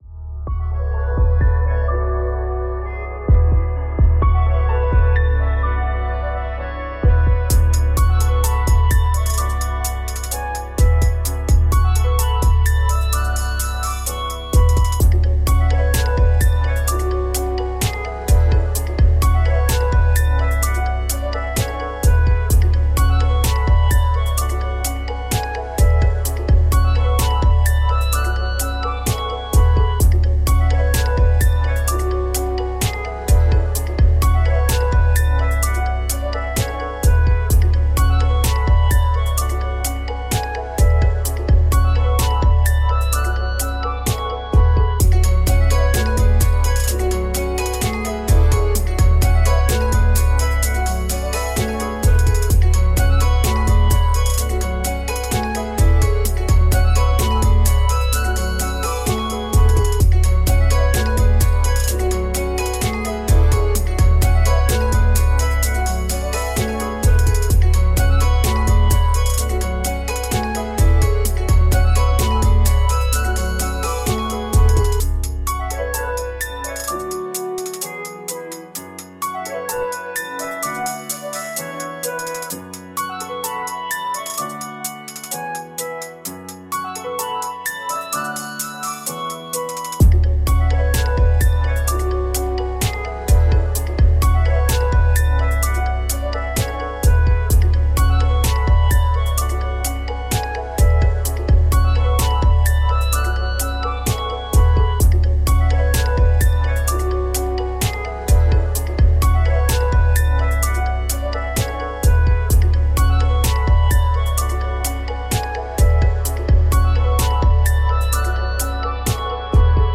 Musique Rap, trap, boombap libre de droit pour vos projets.
beatmaker de l'underground